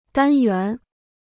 单元 (dān yuán): unit (forming an entity); element.